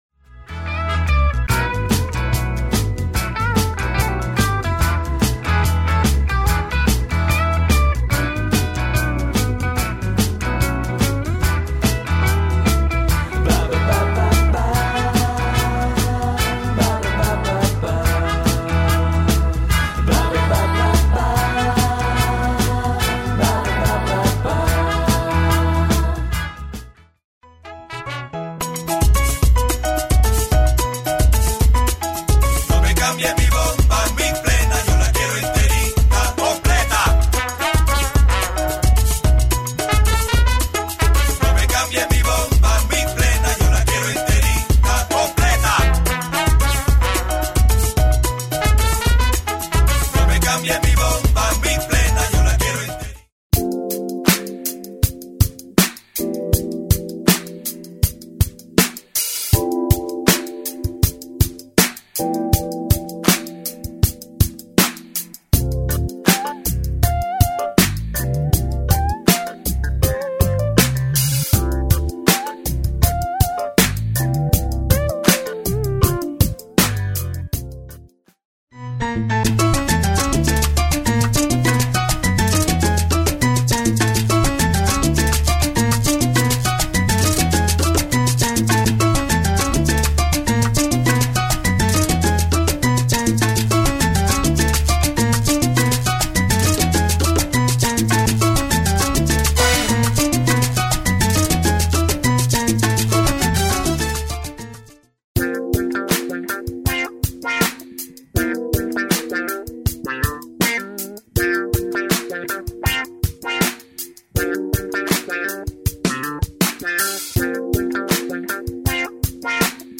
styl - chillout/lounge/jazz/pop